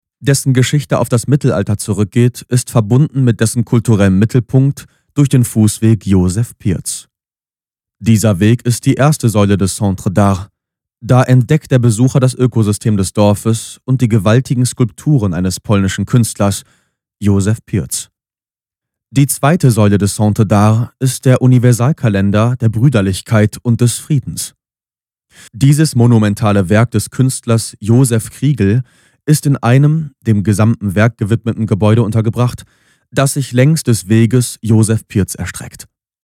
sehr variabel, hell, fein, zart
Jung (18-30)
Audioguide